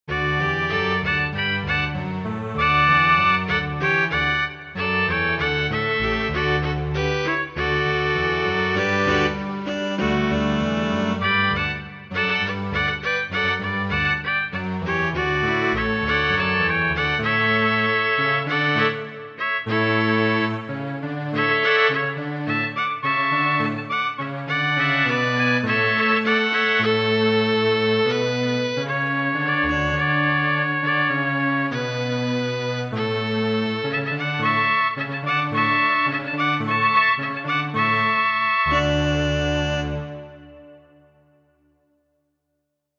Muziko:
Halelujo! de la oratorio Mesio de G. F. Haendel.